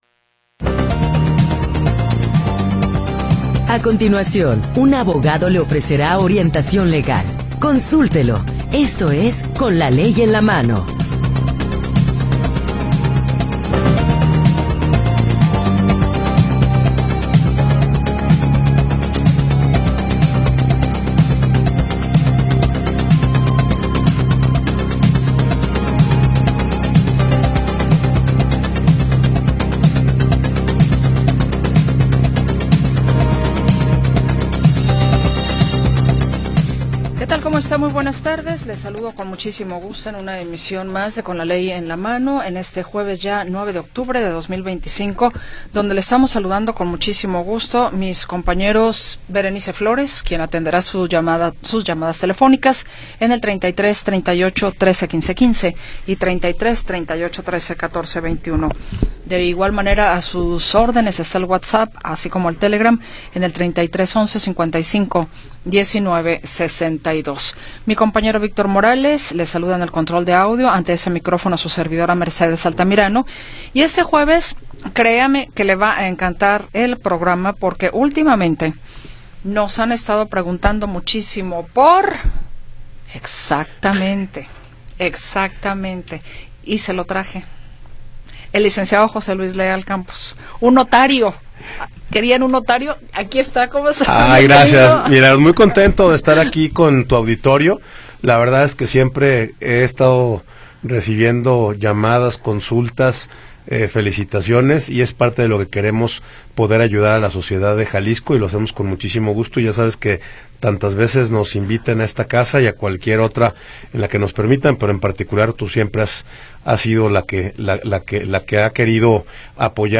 Orientación legal de jueces y abogados especialistas